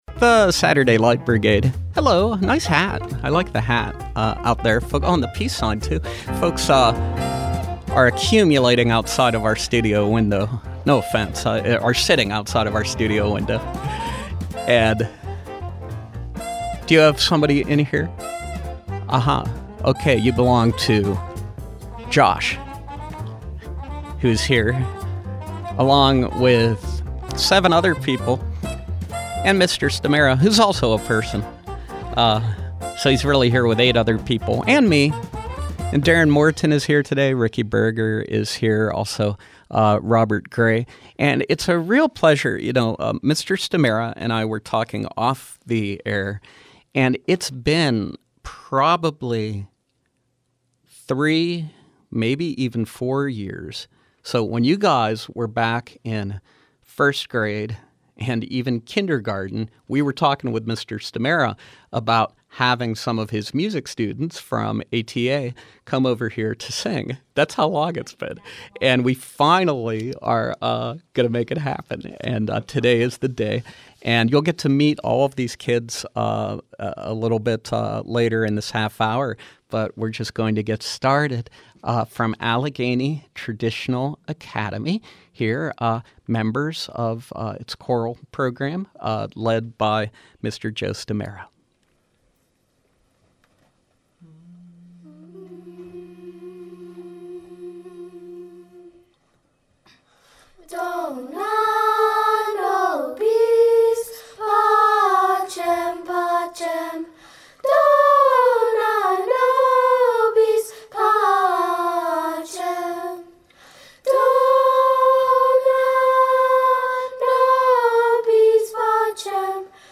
In our second installment of features highlighting Pittsburgh Public Schools’ All-City Arts Showcases, we focus on Music. Tune in to hear the choir from Pittsburgh Allegheny K-5 performing live on SLB.